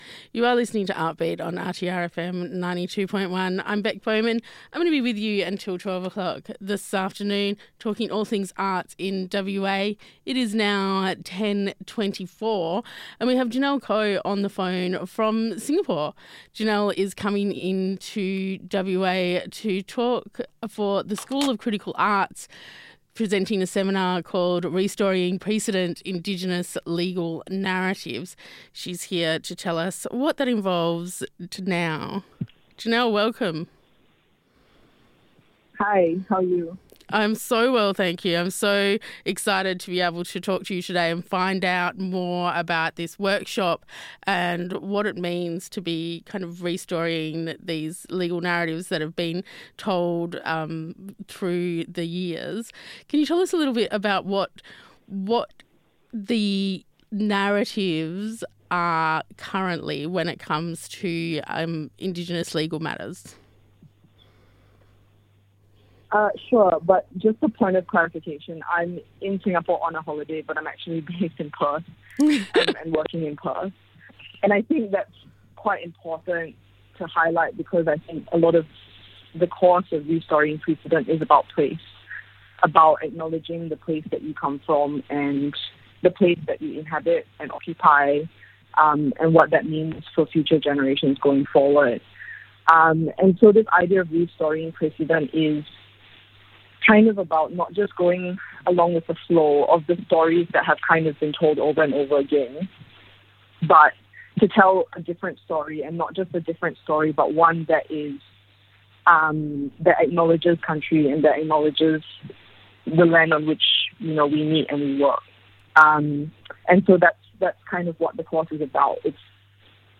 school-of-critical-arts-interview.mp3